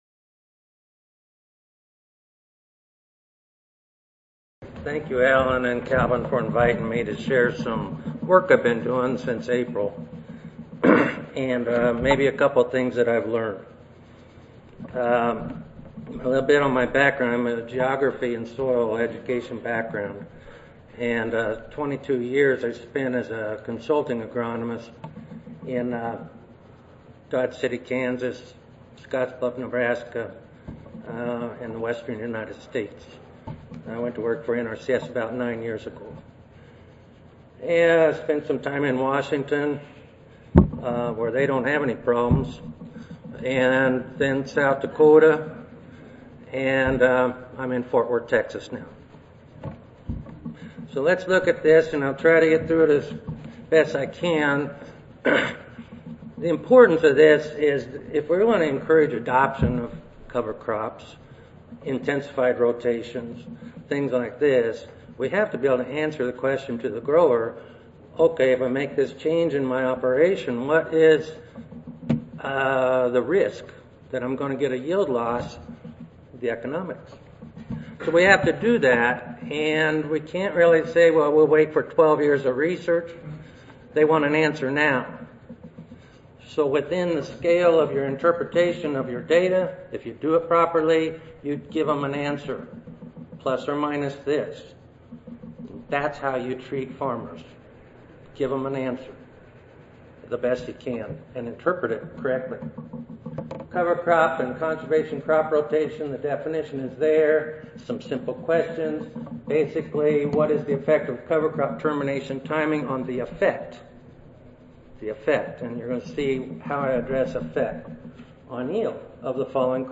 USDA-ARS Recorded Presentation Audio File 9:30 AM 318-5 Adaptation Of Cover Crops In The Texas Rolling Plains.